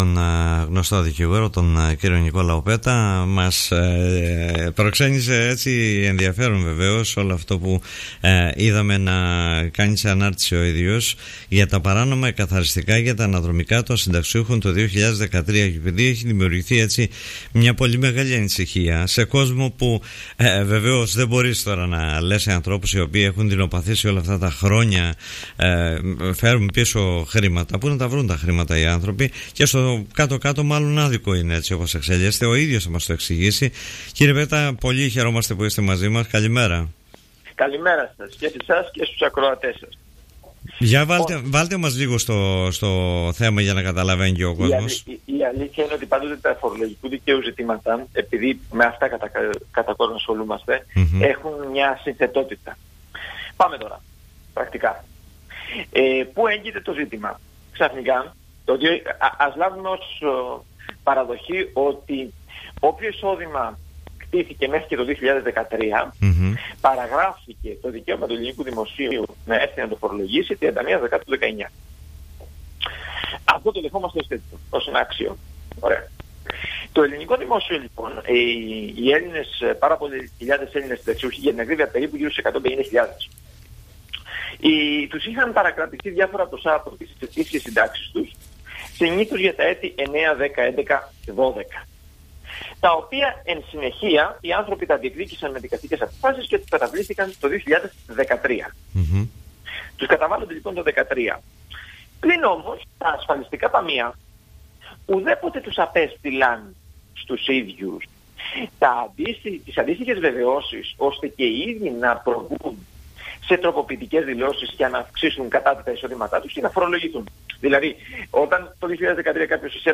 μίλησε στον Politica 89.8